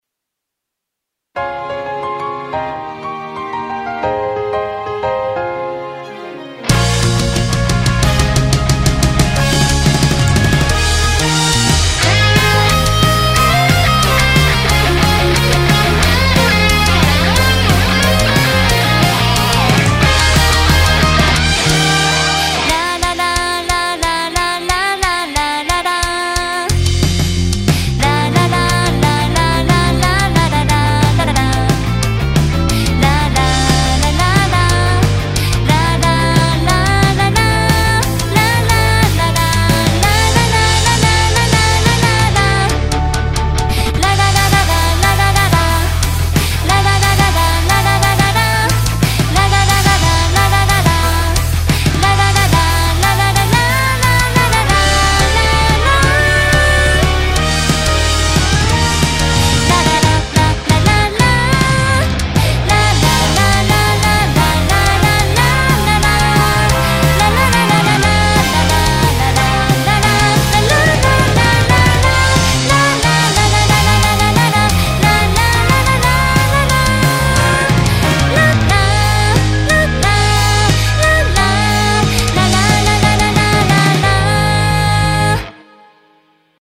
周年曲とかスペレコっぽいの意識して作曲してみてるんですが歌詞が全く書けません